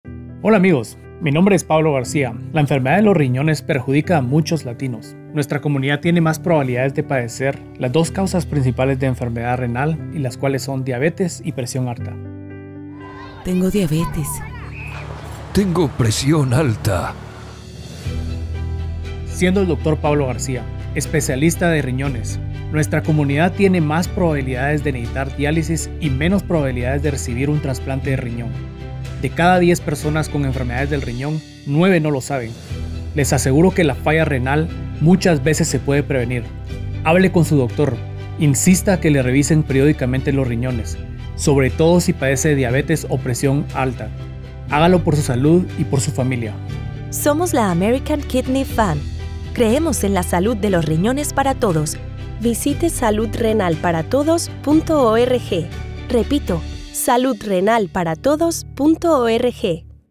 Broadcast-quality PSAs available at no cost to your station.